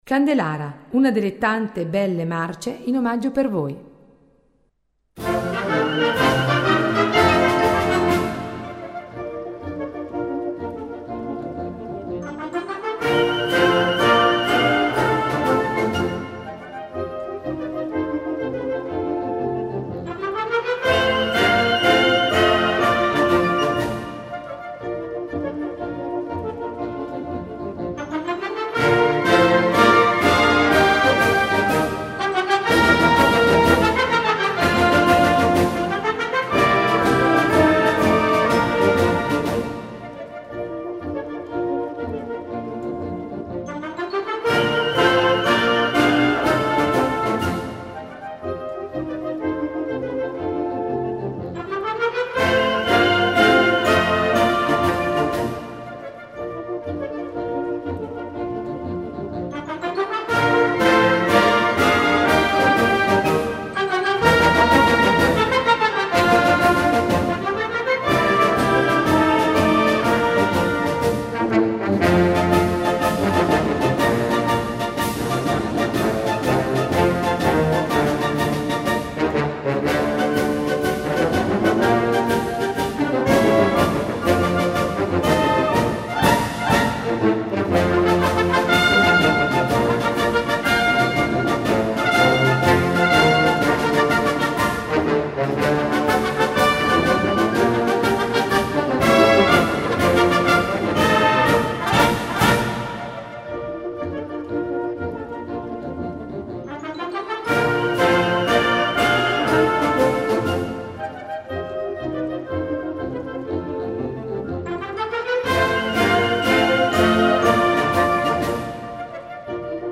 Marcia in 6/8